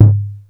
DB - Percussion (13).wav